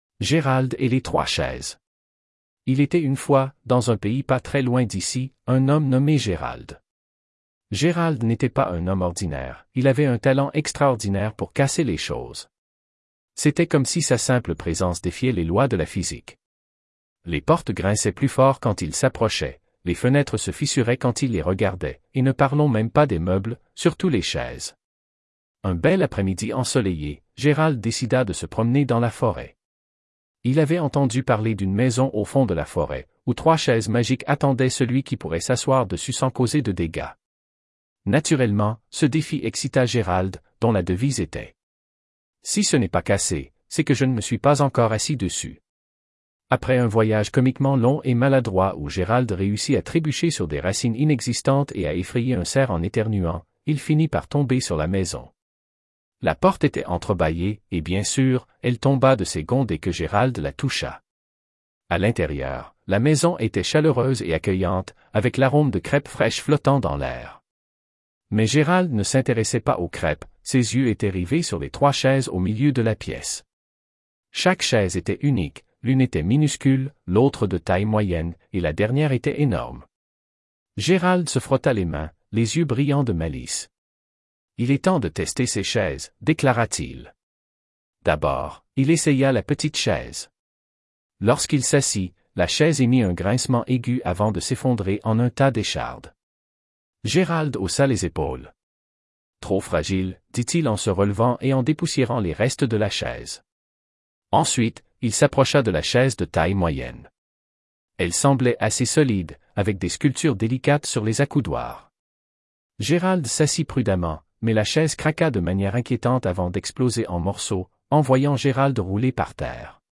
Merci IA